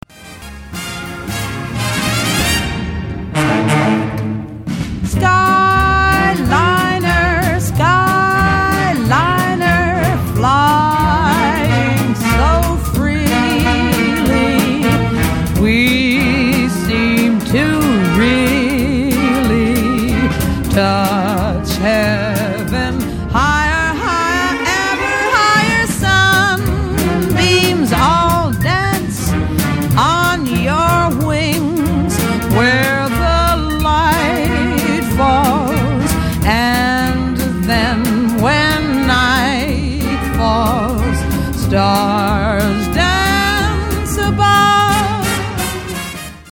in her lusty, full-throated, powerful voice.